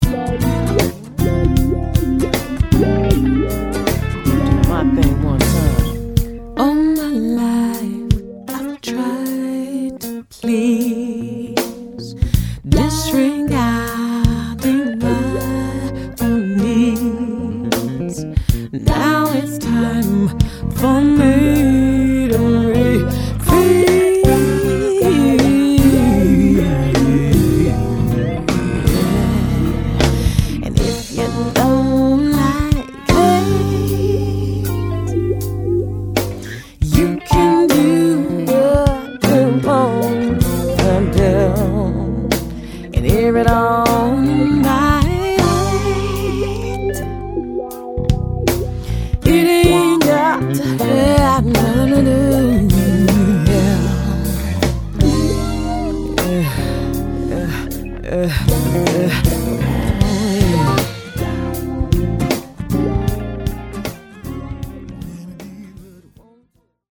in uno stile pop-soul, molto raffinato e “black”